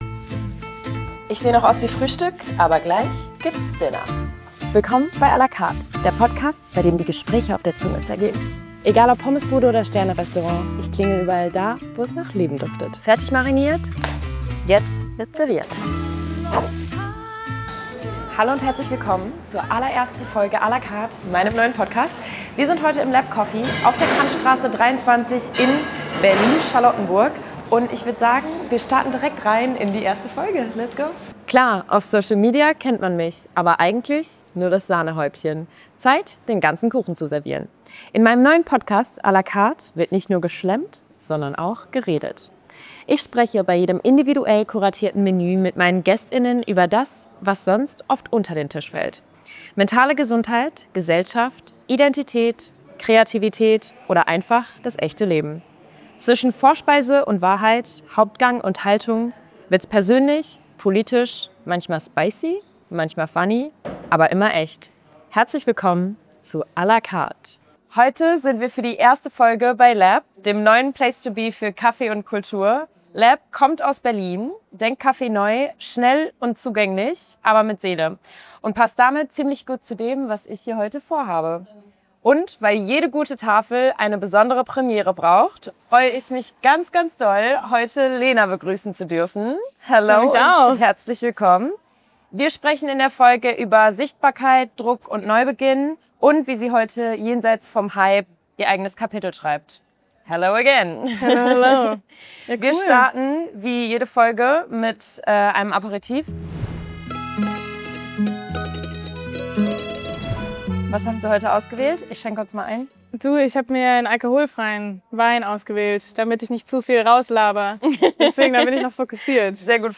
Zu Cookies und Wein sprechen die beiden in der ersten Folge “à la Carte” über das Thema Selbstfindung.